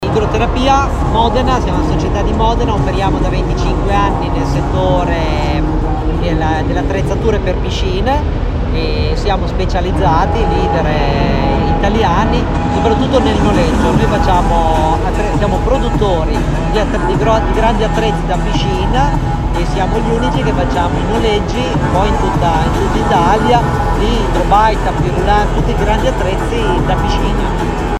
RIMINI WELNESS - Radio International Live